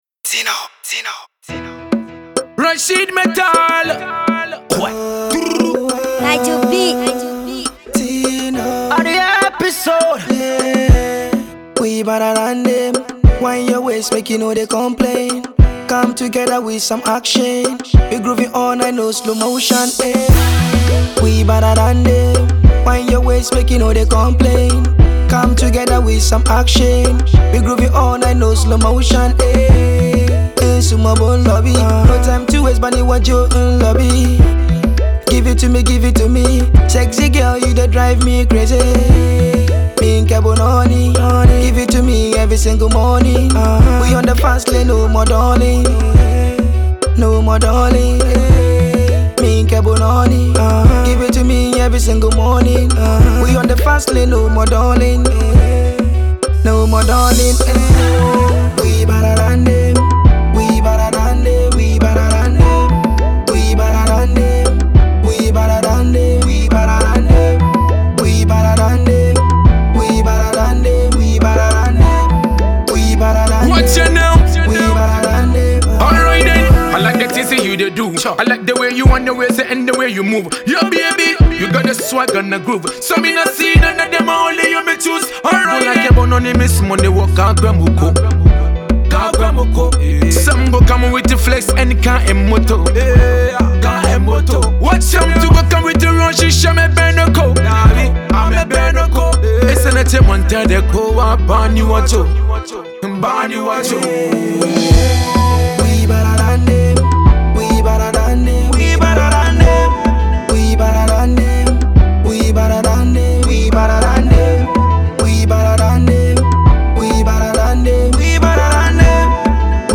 It’s a great pop song